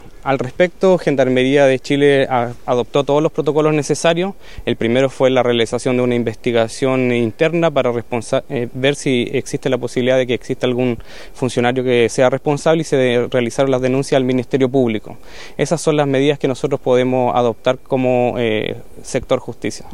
El seremi de Justicia y Derechos Humanos, Cristóbal Fuenzalida, explicó que se indaga si hay responsabilidades de quienes están a cargo de la seguridad en las cárceles.